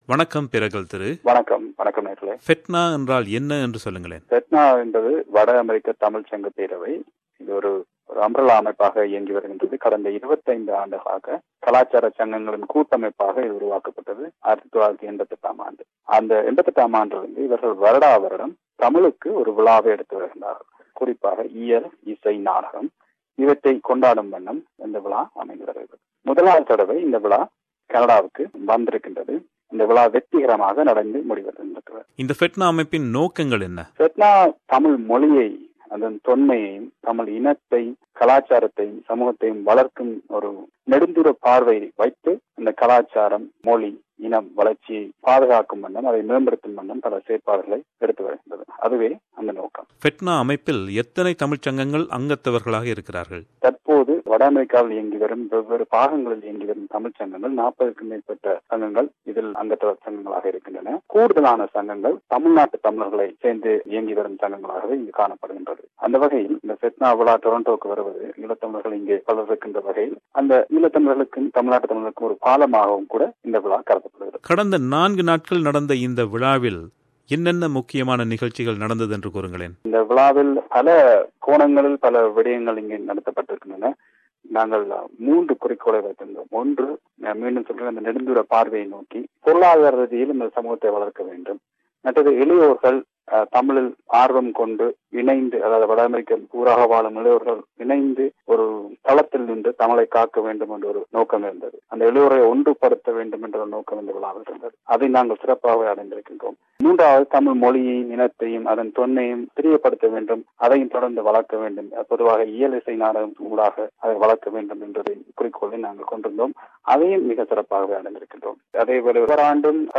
சந்தித்து அது பற்றி உரையாடுகிறார்.